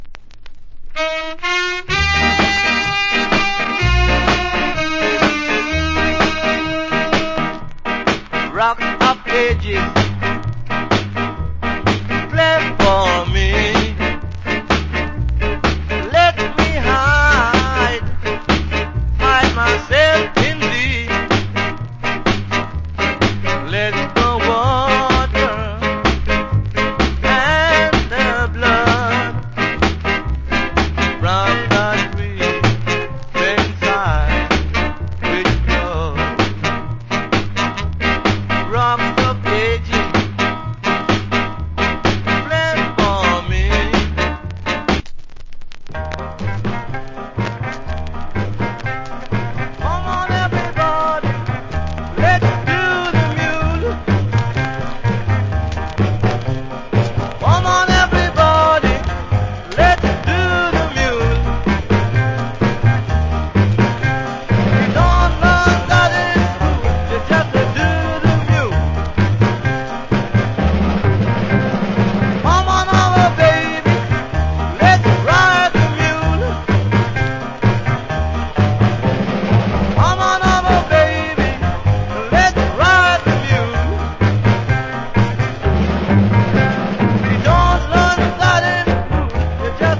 コメント Nice Ska Vocal. / Wicked Vocal.